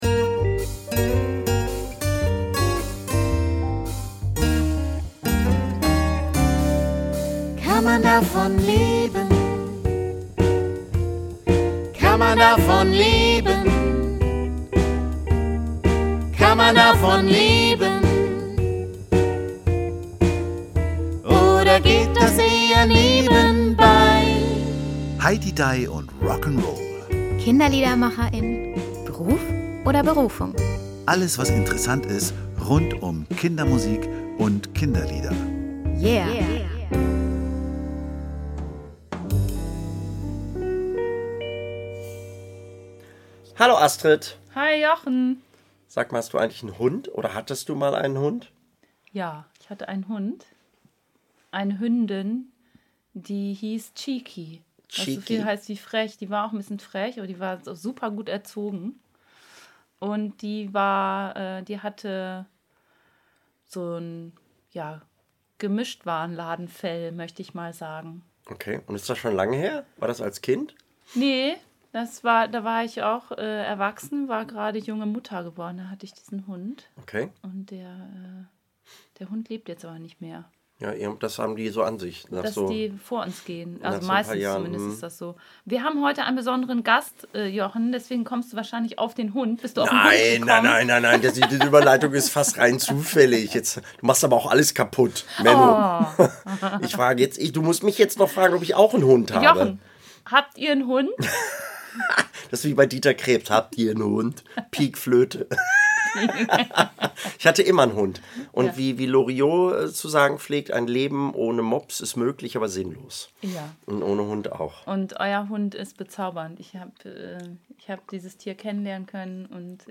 eine hinreißende Mischung aus Funk, Reggae und Pop
richtig gute Musik für Kinder!